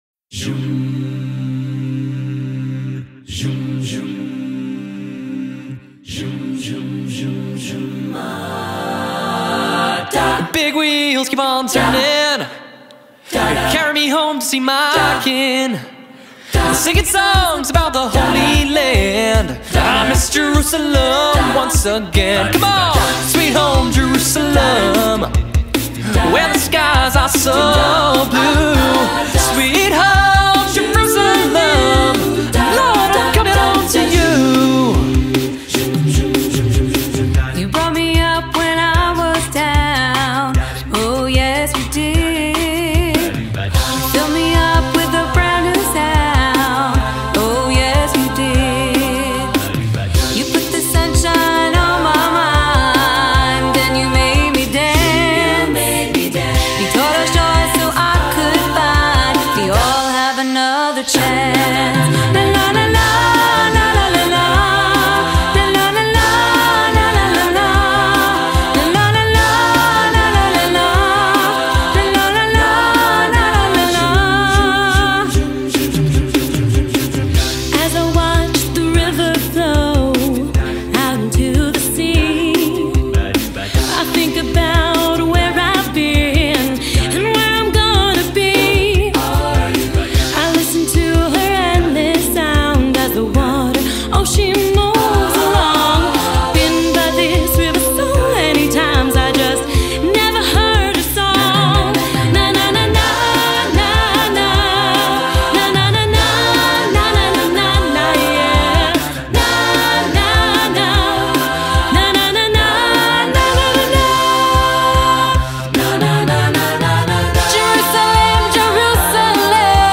Genre: Religious
Contains solos: Yes